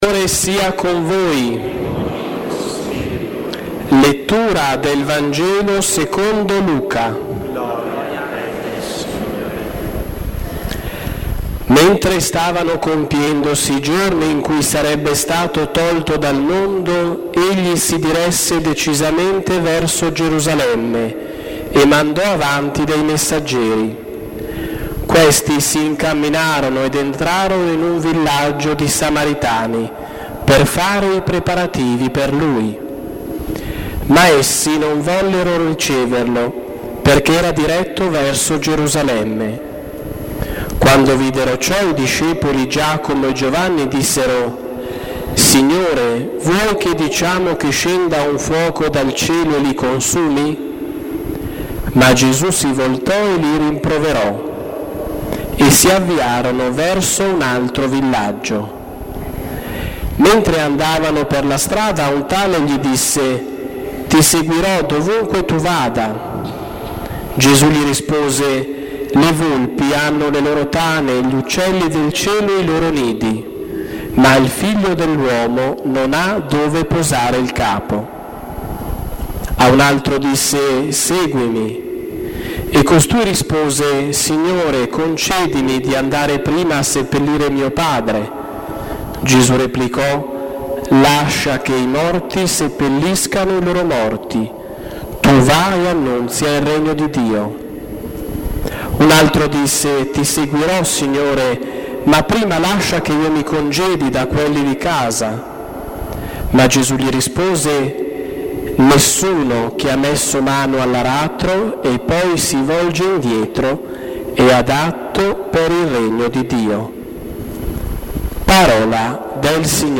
Sono pubblicati anche gli audio delle riflessioni serali
Basilica SS. Filippo e Giacomo